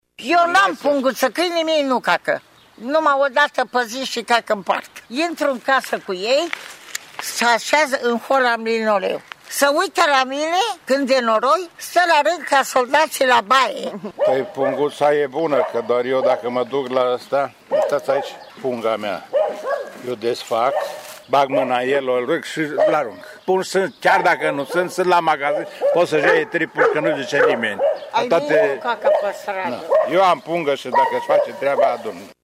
Iubitorii de animale din Tg.Mureș spun că au deja pungi pentru a aduna murdăria făcută de patrupede. Cei care nu au susțin că animalele lor sunt foarte bine dresate şi nu-şi fac nevoile pe stradă: